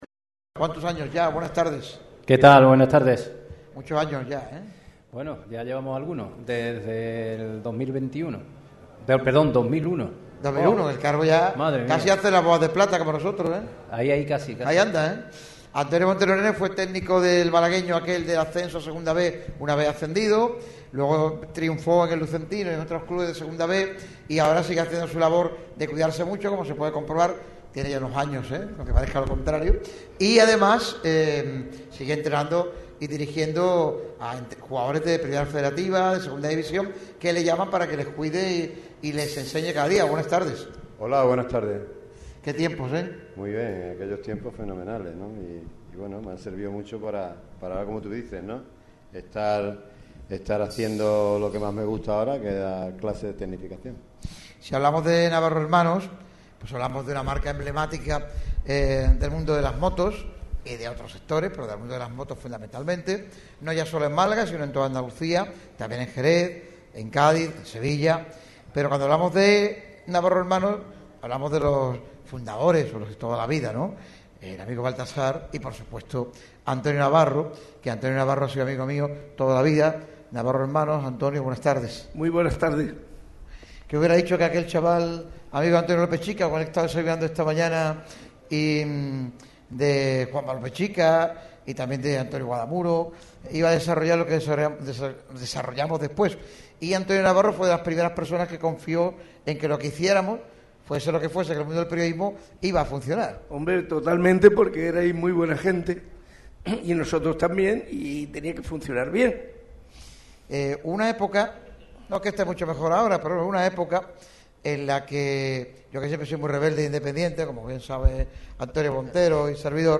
Cervezas Victoria ha acogido uno de esos programas señalados en el calendario de la radio líder del deporte malagueño.